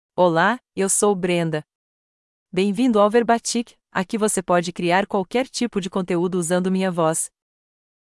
Brenda — Female Portuguese (Brazil) AI Voice | TTS, Voice Cloning & Video | Verbatik AI
Brenda is a female AI voice for Portuguese (Brazil).
Voice sample
Female
Brenda delivers clear pronunciation with authentic Brazil Portuguese intonation, making your content sound professionally produced.